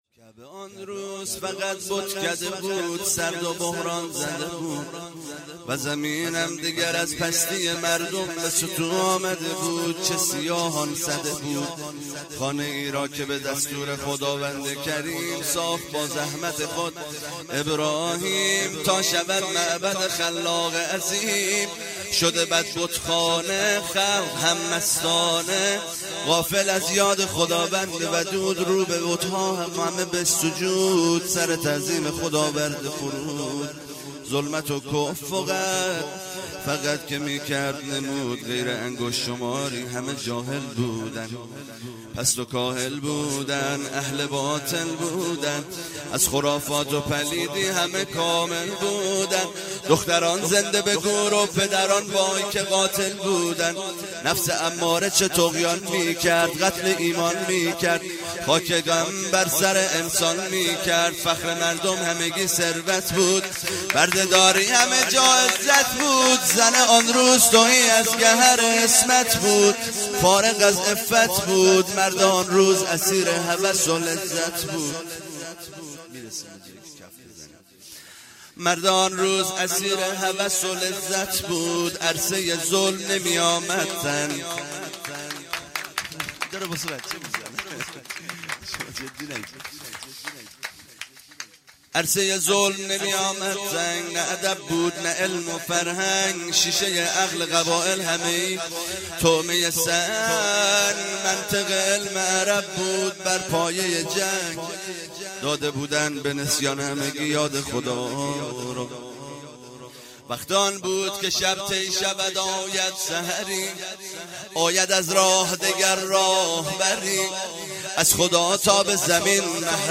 0 0 مدح
جشن مبعث - جمعه24 فروردین 1397